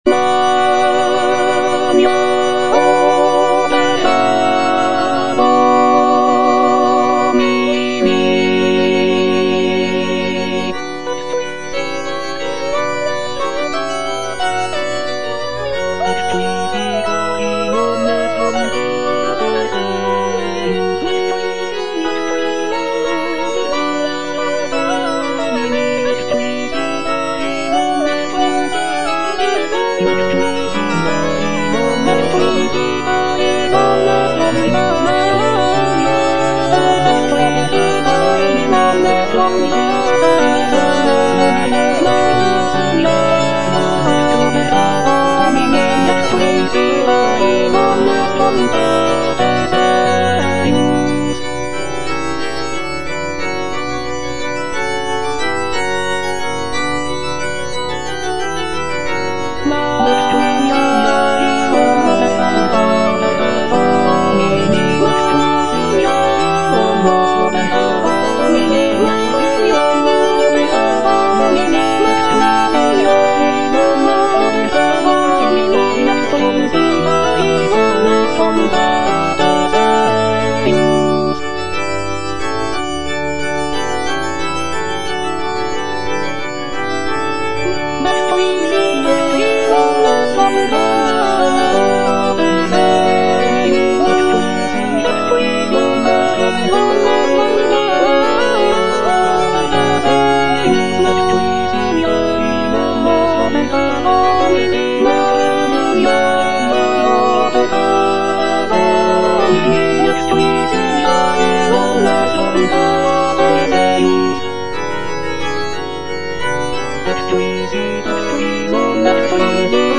M.R. DE LALANDE - CONFITEBOR TIBI DOMINE Magna opera Domini - Alto (Emphasised voice and other voices) Ads stop: auto-stop
"Confitebor tibi Domine" is a sacred choral work composed by Michel-Richard de Lalande in the late 17th century. It is a setting of the Latin text from Psalm 111, expressing gratitude and praise to the Lord. Lalande's composition features intricate polyphony, lush harmonies, and expressive melodies, reflecting the Baroque style of the period.